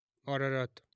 1. ^ /ˈærəræt/, ARR-ə-rat; Armenian: Արարատ, romanizedArarat [ɑɾɑˈɾɑt]